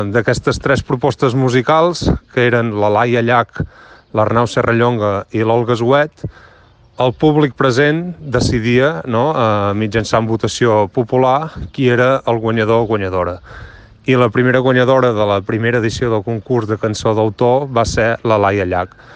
Així ho explicava l’alcalde de Verges, Ignasi Sabater, en declaracions a Ràdio Capital